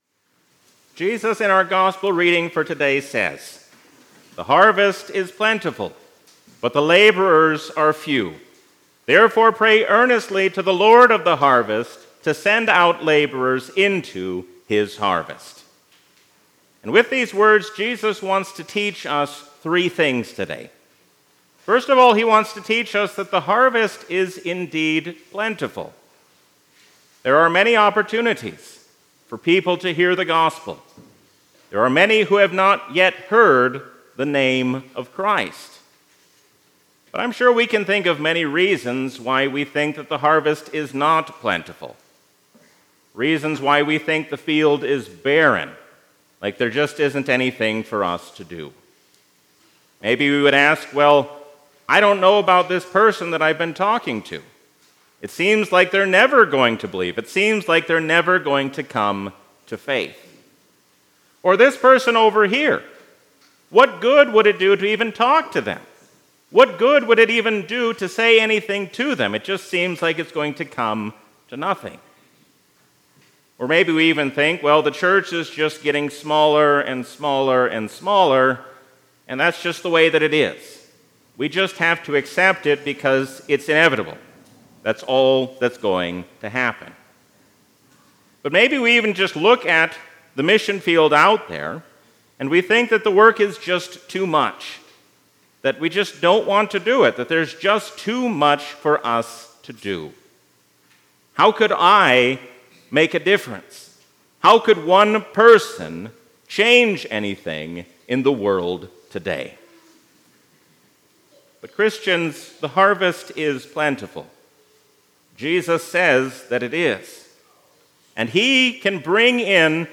A sermon from the season "Trinity 2023." We are called to faithfully carry out the work given to us while we wait for the Last Day.